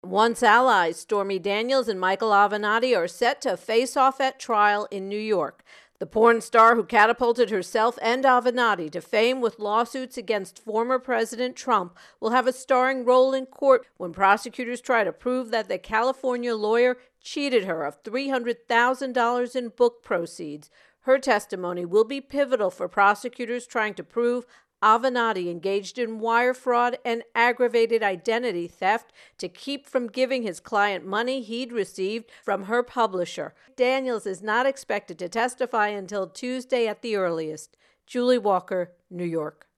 Michael Avenatti Stormy Daniels Intro and Voicer